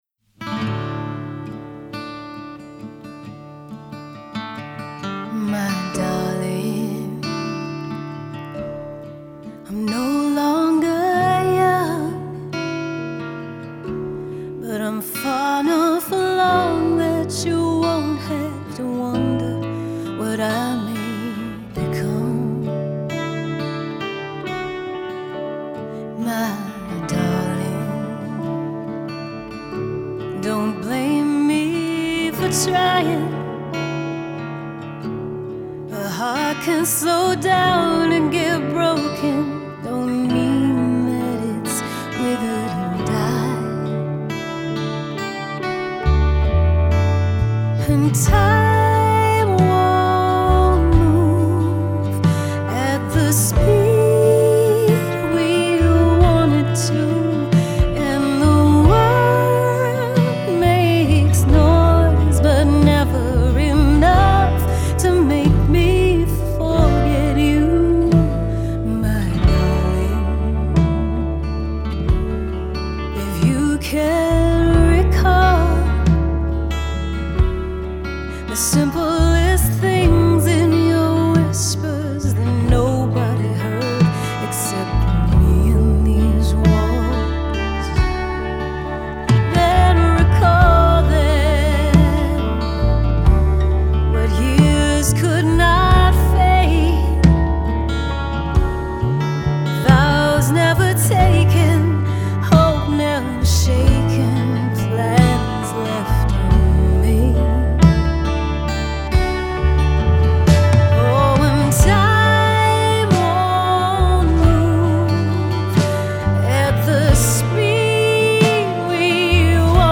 Lead and backing vocals
Keyboards
Guitars
Bass
Drums and percussion